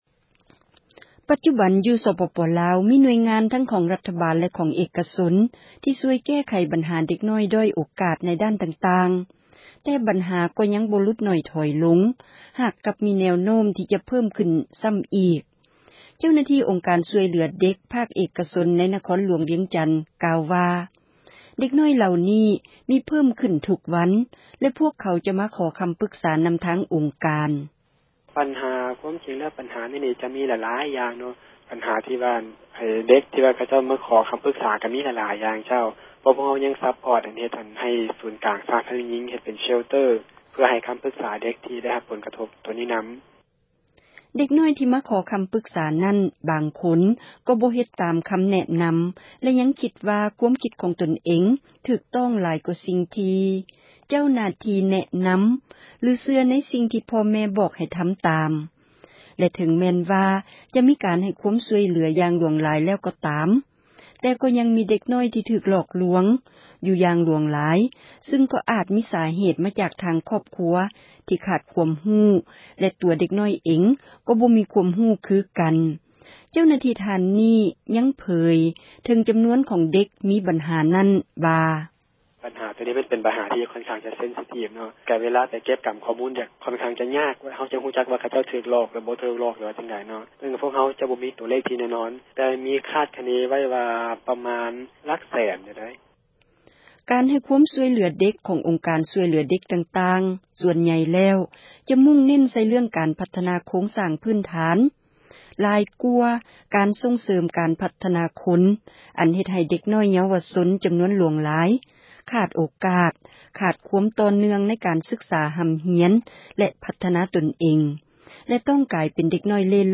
ແຮງງານເດັກ ມີ ແນວໂນ້ມ ວ່າ ຈະເພີ່ມຂຶ້ນ ໃນ ສປປລາວ — ຂ່າວລາວ ວິທຍຸເອເຊັຽເສຣີ ພາສາລາວ